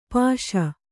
♪ pāśa